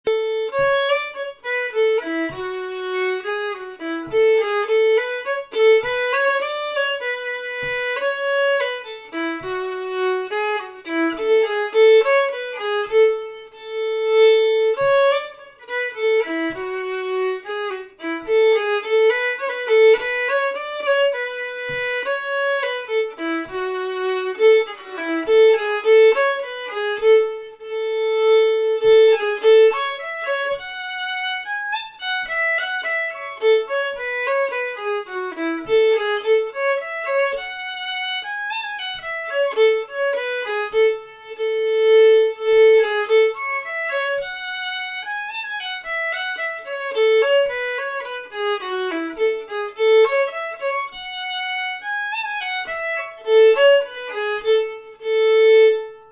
Key : A
Waltz MP3